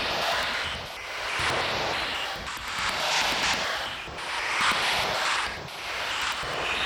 Index of /musicradar/stereo-toolkit-samples/Tempo Loops/140bpm
STK_MovingNoiseF-140_01.wav